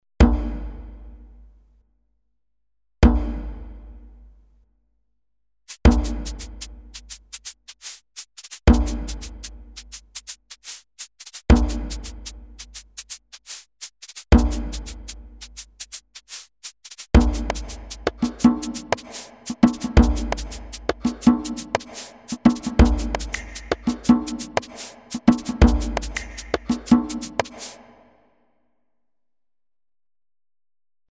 Fertige Dance-Presets
Viele der Dance-Presets sind sehr straight; teilweise trifft man auch auf Vorlagen, die kein vollständiges Paket aus Grooves liefern, sondern lediglich Entwürfe für eine eigene Ausarbeitung darstellen.